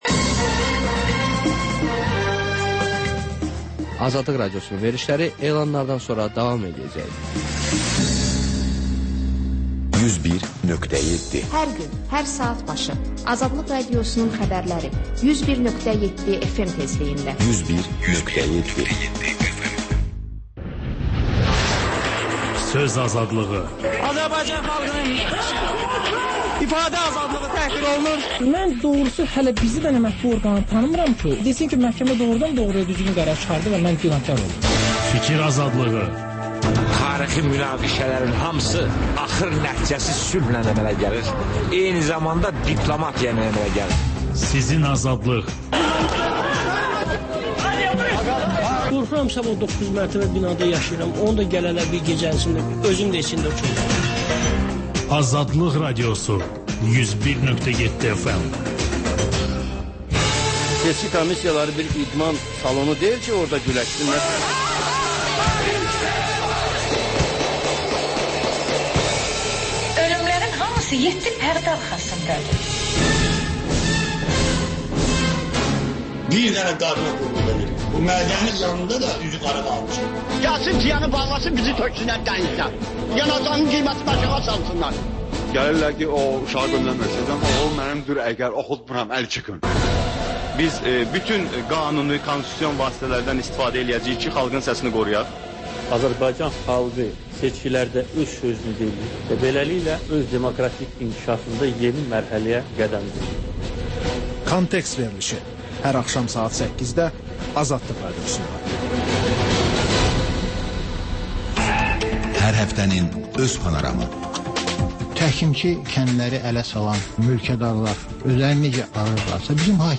Xəbərlər, sonra CAN BAKI: Bakının ictimai və mədəni yaşamı, düşüncə və əyləncə həyatı… Həftə boyu efirə getmiş CAN BAKI radioşoularında ən maraqlı məqamlardan hazırlanmış xüsusi buraxılış